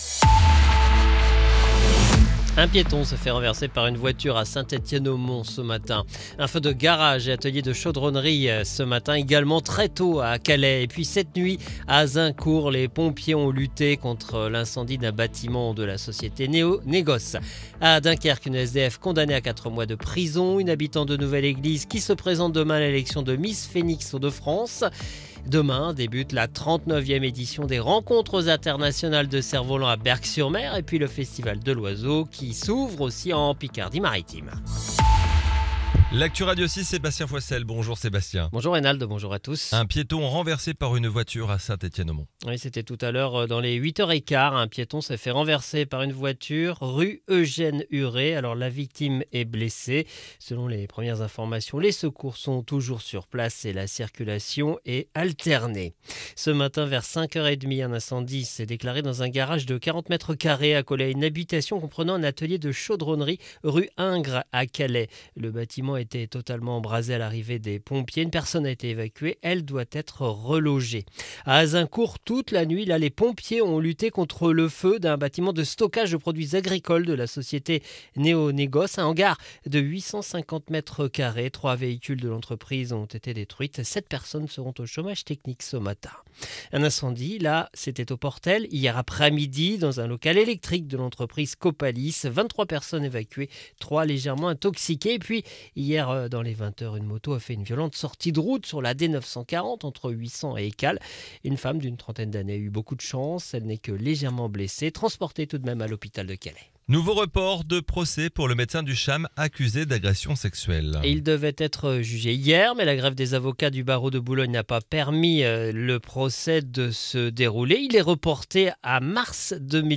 Le journal de vendredi 17 avril 2026